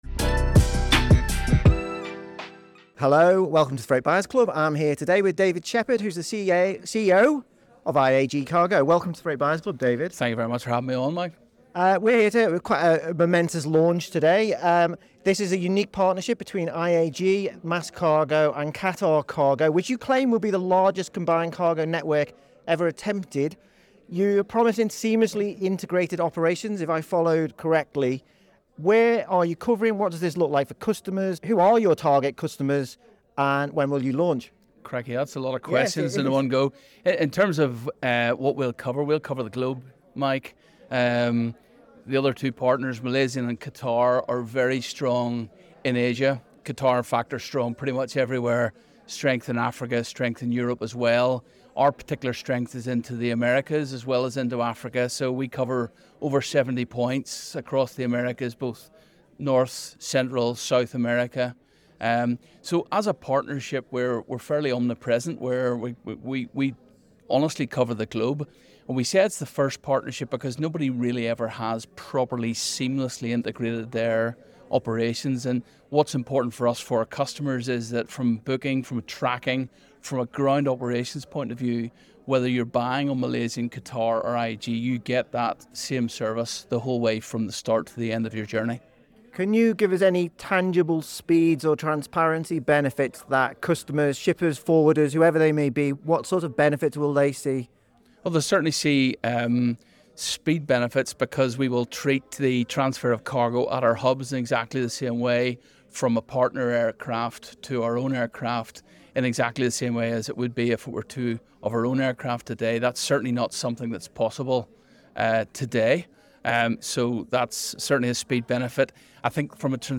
In this exclusive interview with The Freight Buyers Club